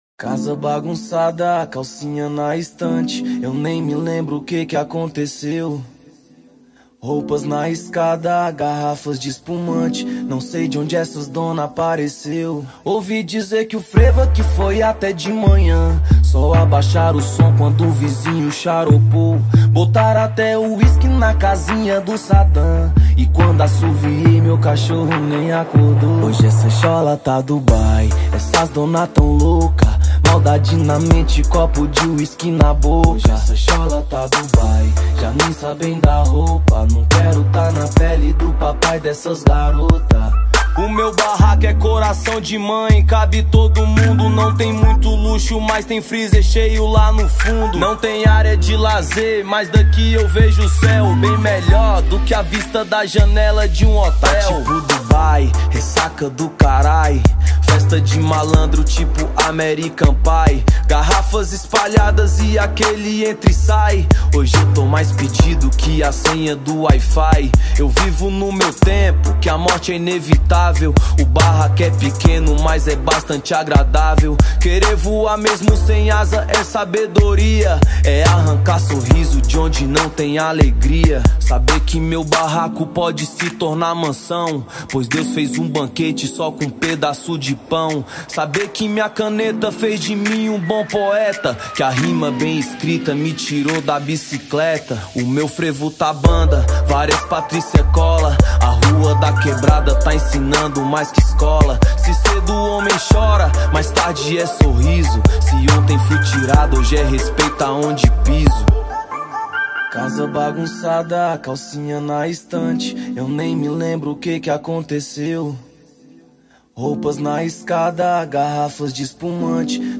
2024-05-10 21:17:57 Gênero: Hip Hop Views